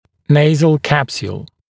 [‘neɪzl ‘kæpsjuːl][‘нэйзл ‘кэпсйу:л]носовая капсула